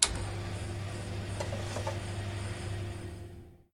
dispense0.ogg